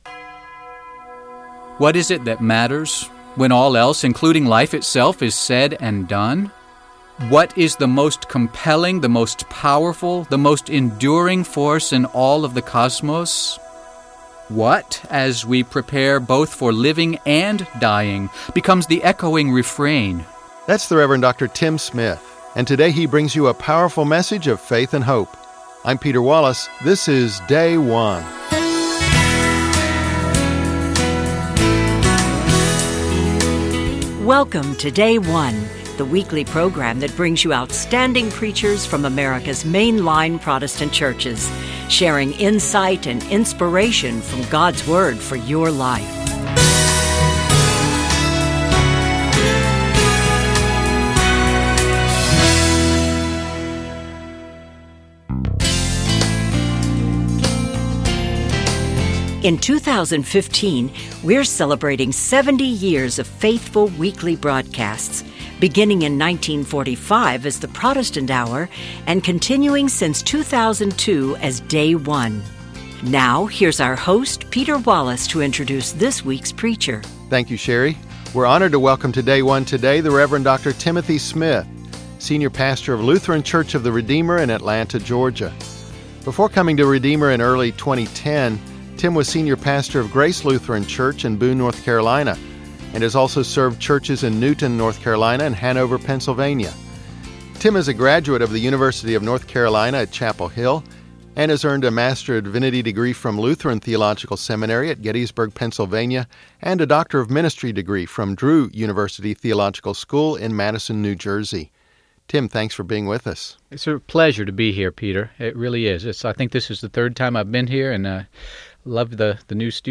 Evangelical Lutheran Church in America 6th Sunday of Easter - Year B John 15:9-17